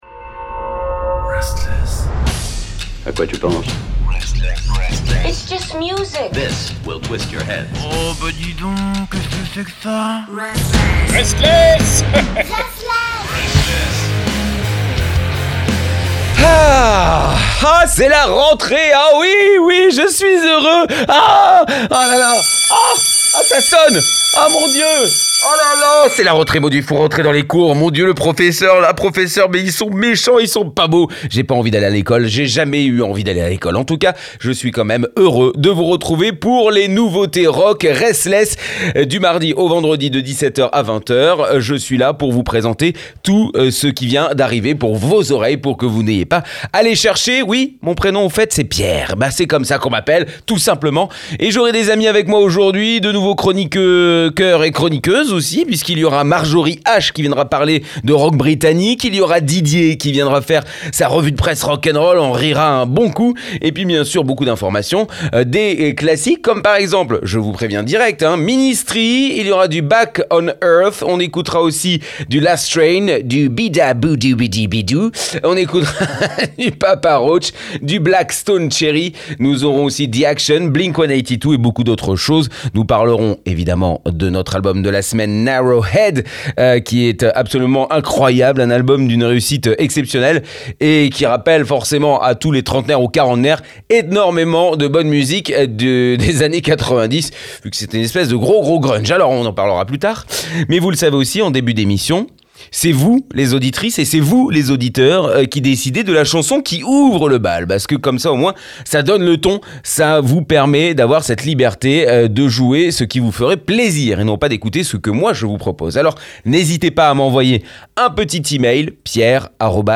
Les nouveautés rock